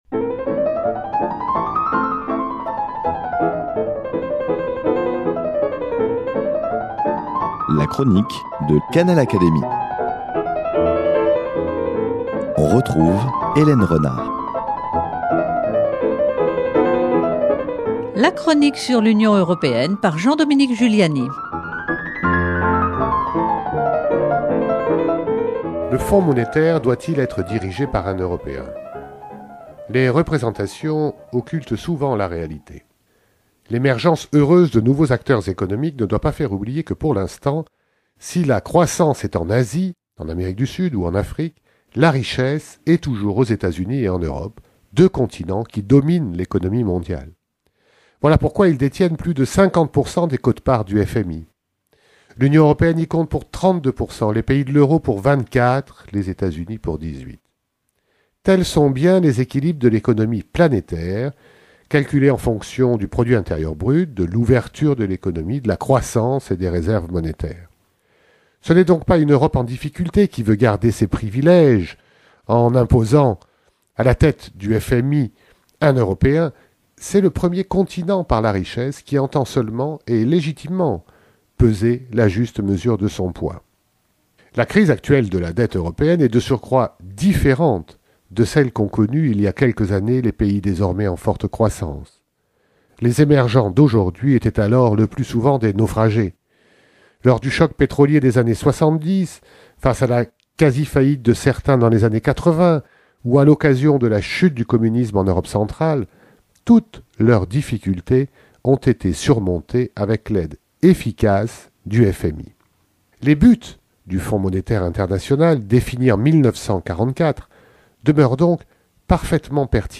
Une nouvelle chronique européenne